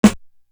Live On Stage Snare.wav